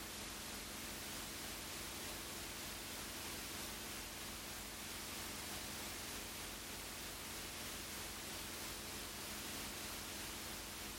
There is a frequency spike at 50 Hz, and the region between 200Hz and 315Hz is also high.
I have recorded the signals shown above, but please keep in mind that I’ve enabled Automatic Gain Control (AGC) to do so to make it easier for you to reproduce them.
30% Fan Speed